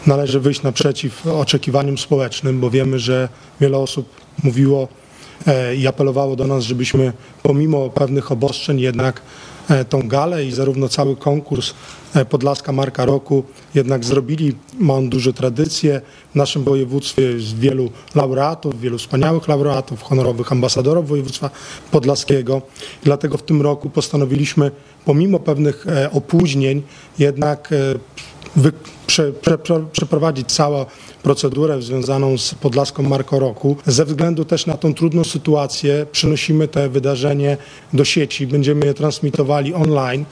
– Uroczystość odbędzie się bez udziału publiczności, a gala będzie transmitowana na żywo online – mówi Artur Kosicki, marszałek województwa podlaskiego.